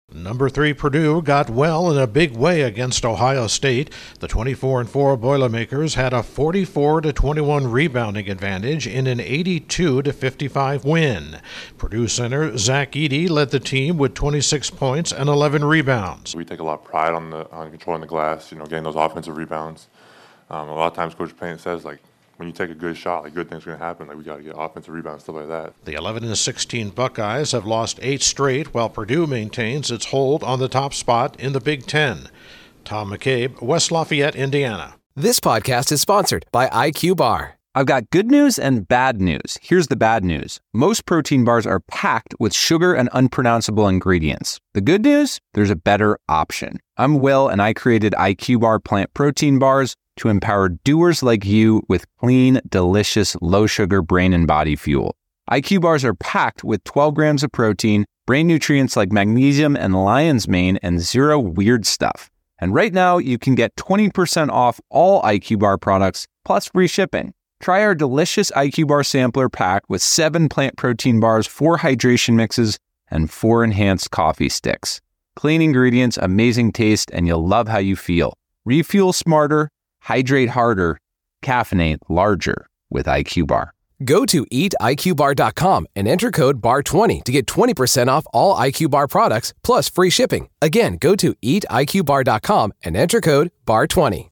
Controlling the glass was a key in Purdue's Big Ten win over Ohio State. Correspondent